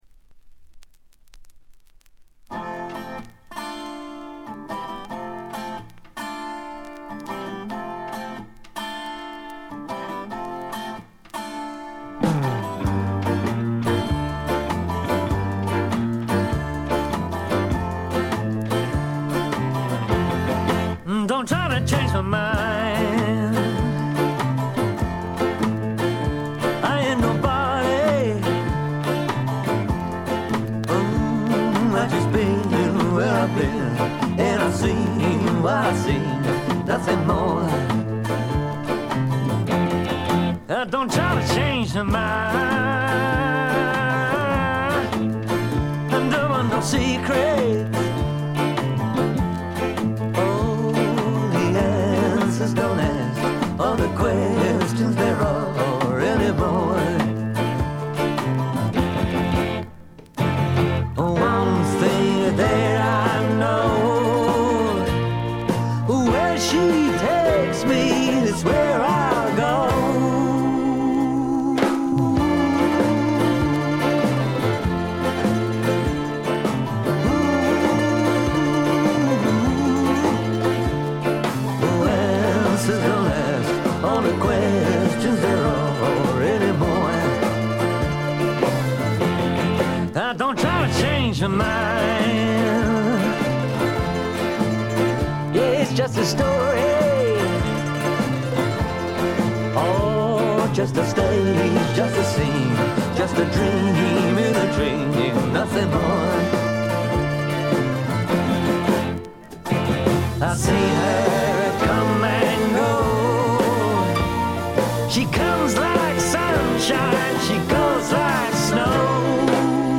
バックグラウンドノイズ、細かなチリプチ多め大きめ。
特に目立つノイズはありません。
試聴曲は現品からの取り込み音源です。